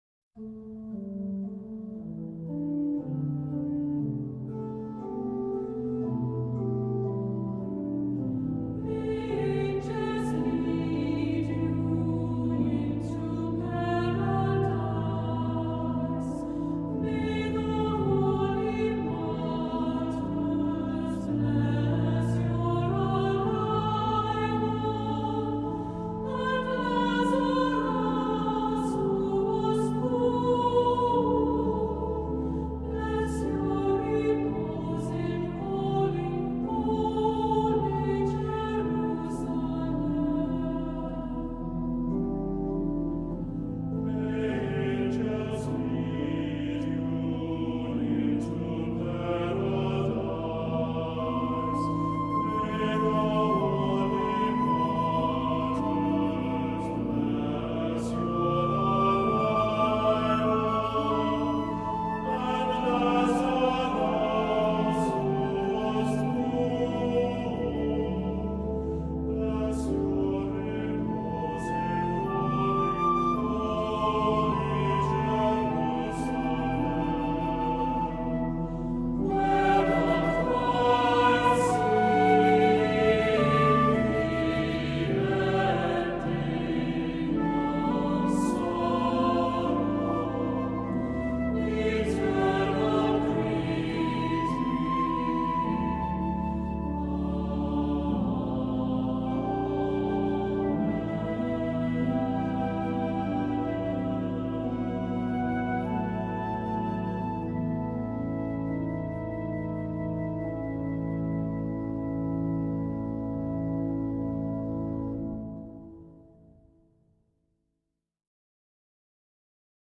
Voicing: Unison Choir